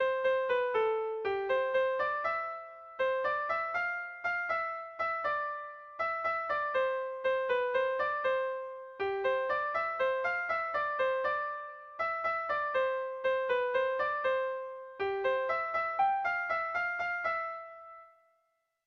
Sentimenduzkoa
AB1B2